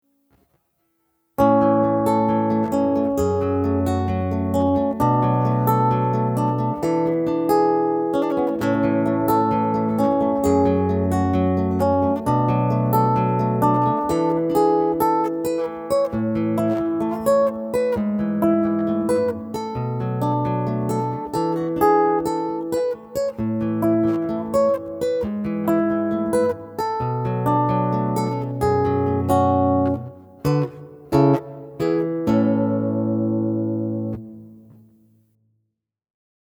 Если интересно - записал гитару аудио и параллельно эл.пианино миди треком. Ничего с треками не делал, это что бы понять насколько удобно забивать клавишные партии через гитару.